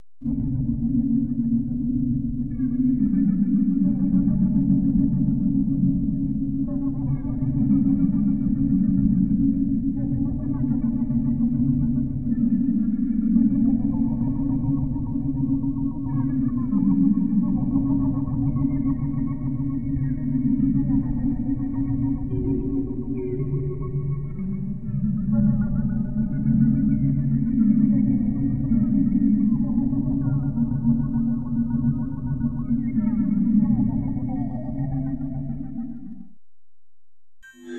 На этой странице собраны звуки пустоты – завораживающие, мистические и медитативные аудиозаписи.
Звук пустоты в разуме